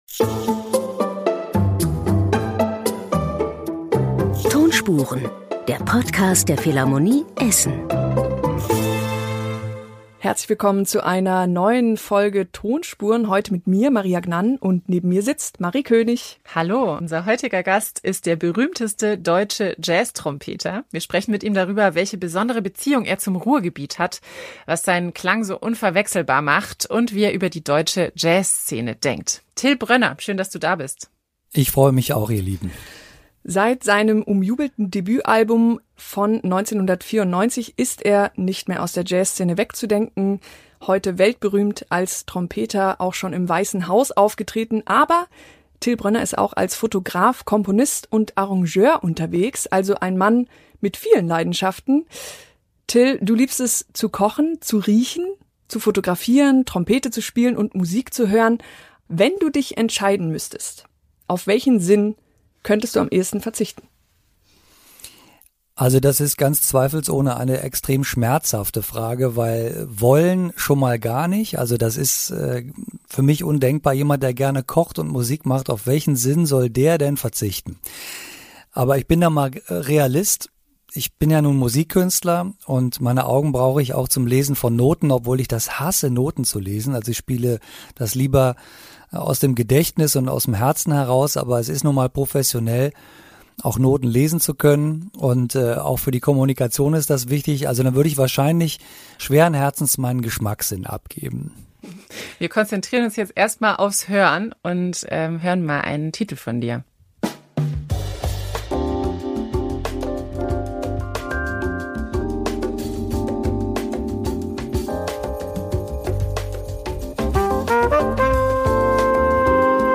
1 «Das vergiftete Spiegelei» von Mary Hottinger und I.P. Scherrer 1:22:04 Play Pause 5d ago 1:22:04 Play Pause Später Spielen Später Spielen Listen Gefällt mir Geliked 1:22:04 Das grosse Schaulaufen der Meisterdetektive! Im humorvollen Hörspiel werden Sherlock Holmes, Maigret, Pater Brown und Co. auf ein- und denselben Fall losgelassen.